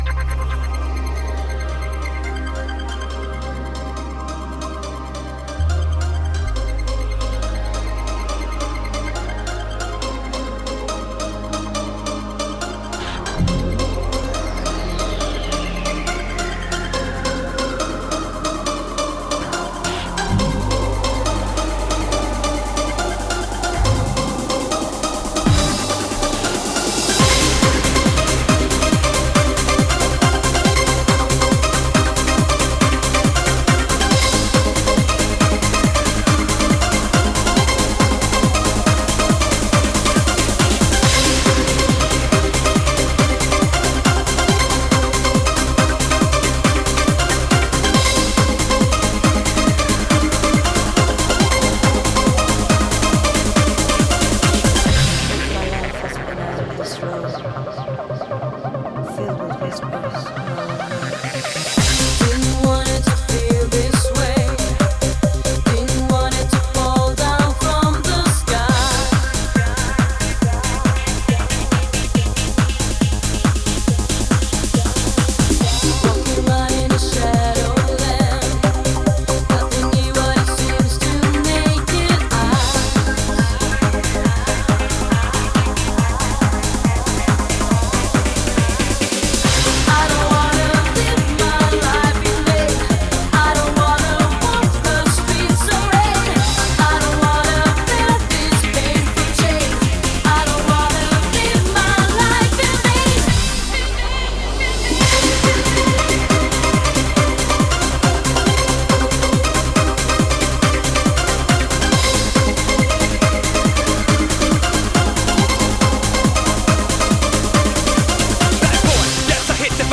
Genre: Dream.